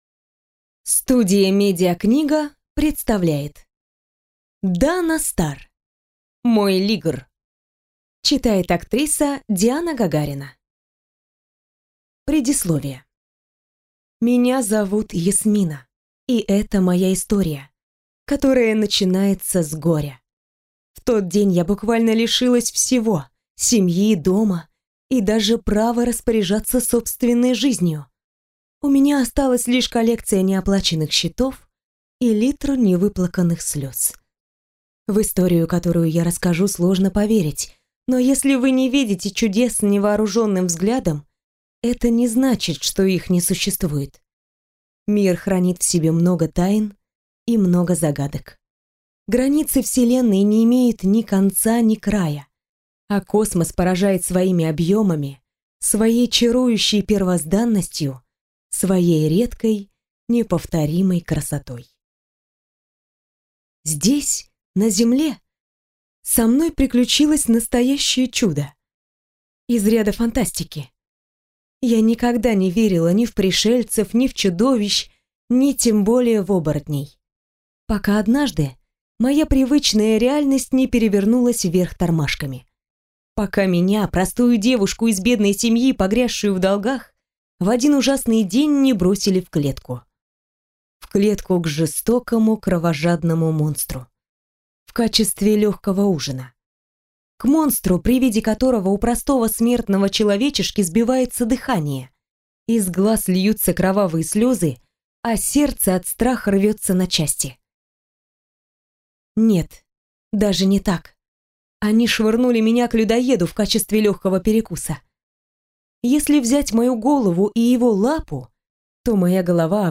Аудиокнига Мой Лигр | Библиотека аудиокниг